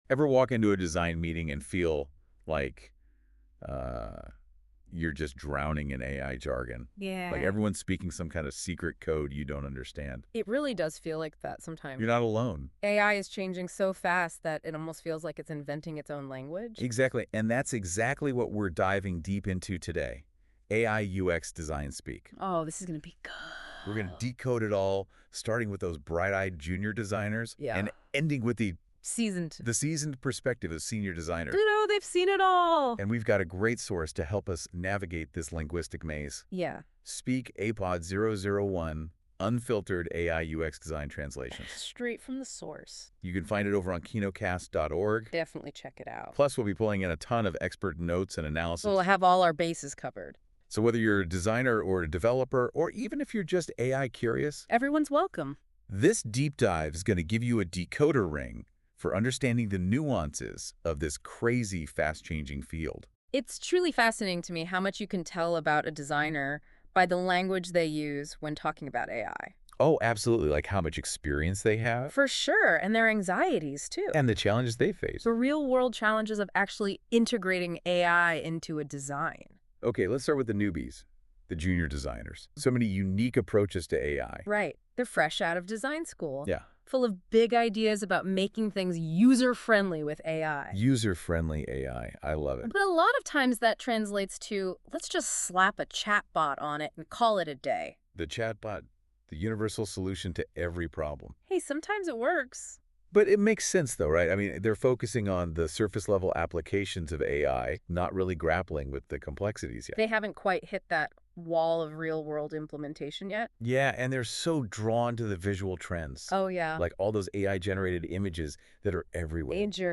Do machines now have consciousness?Welcome to the second AI UX design Nerds Unfiltered AI podcast. I call it an aiPod because I have generated it, with AI also.